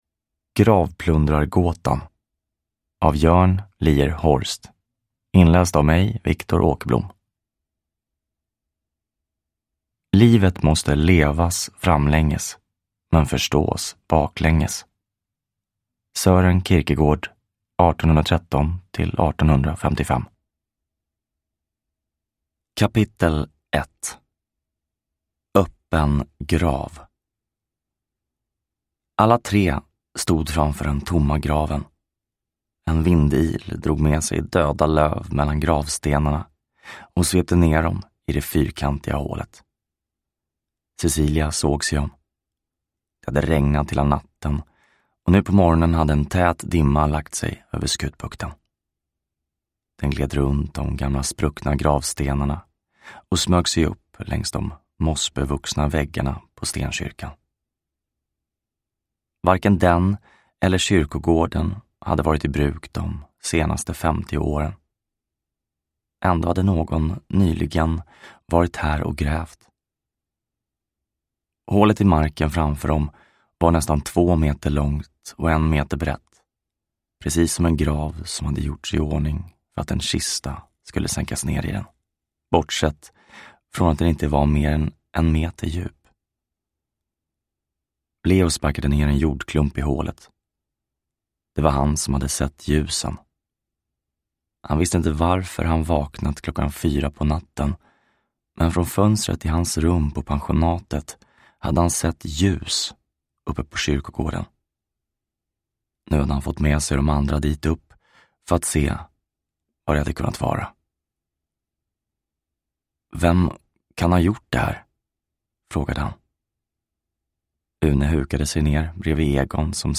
Gravplundrargåtan – Ljudbok – Laddas ner